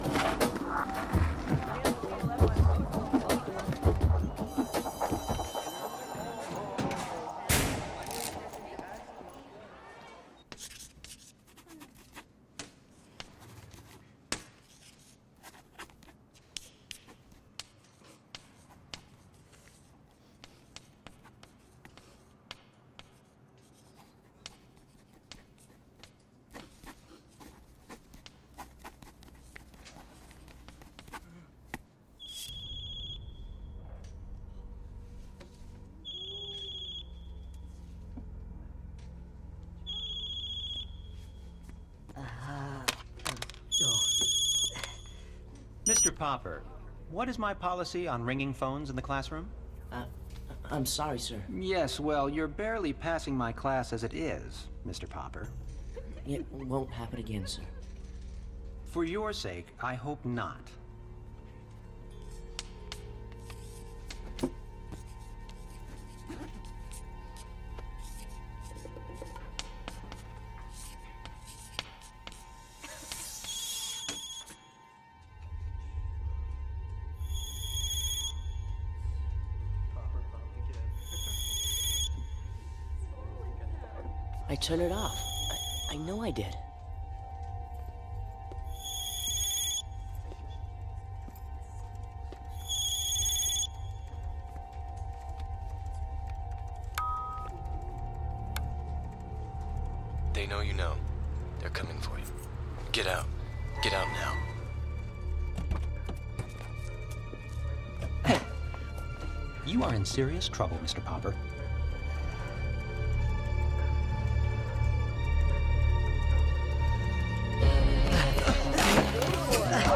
This one is `Kid's Story' directed by Shinichiro Watanabe. A school scene with some math content about linear difference equations.